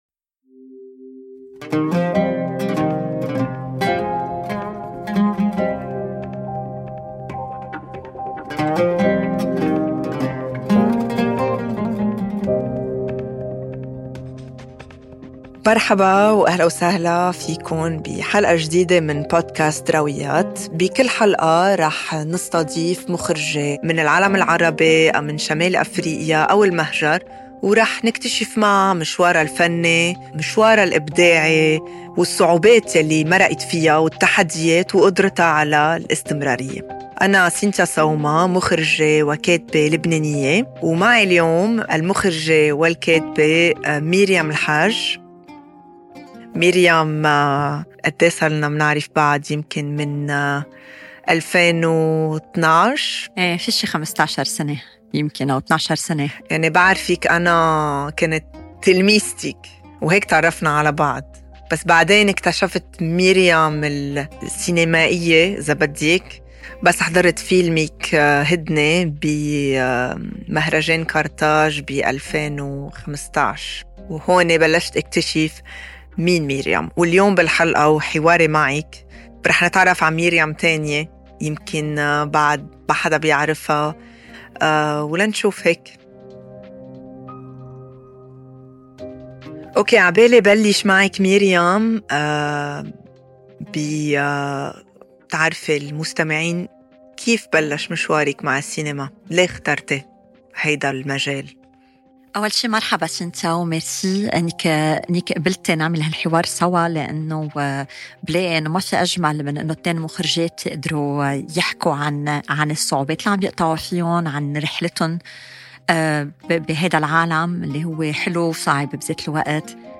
*Episode in Egyptian Arabic, activate subtitles for more languages.